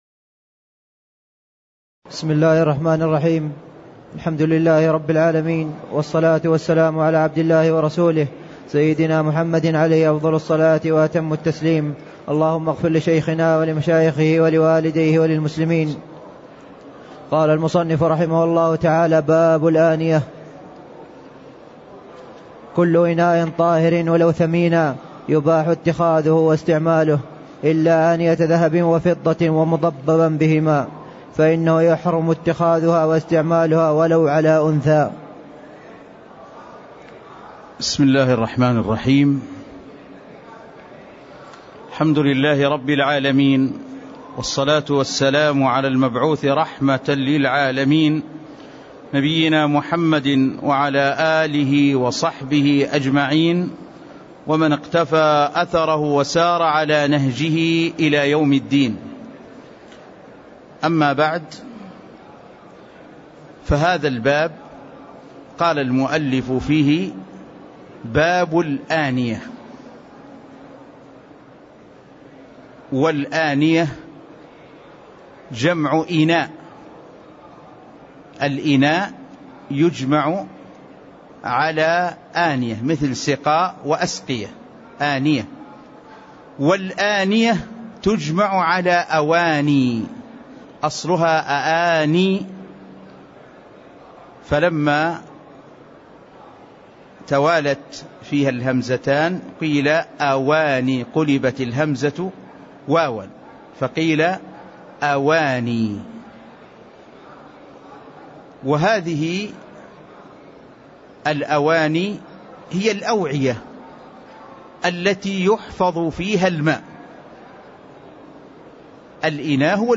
تاريخ النشر ١٦ ربيع الثاني ١٤٣٥ هـ المكان: المسجد النبوي الشيخ